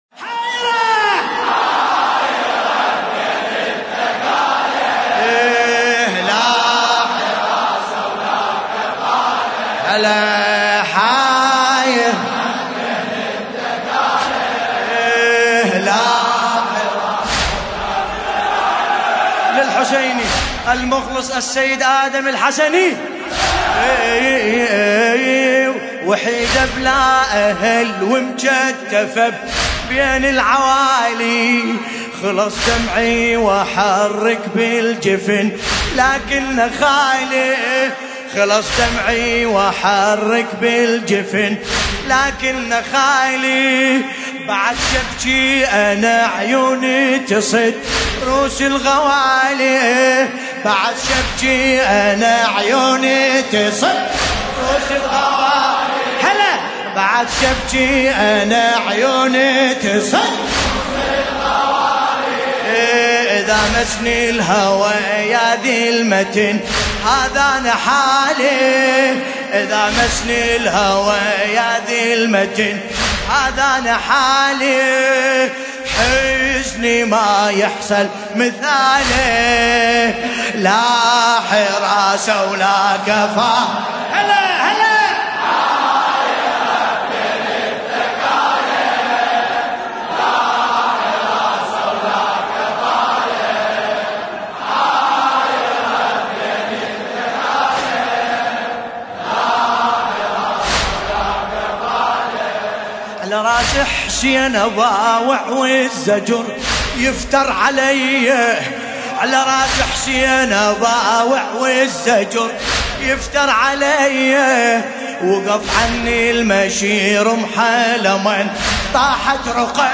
المناسبة : ليلة 16 محرم 1440 هـ
قصيدة
طور : نوم_العوافي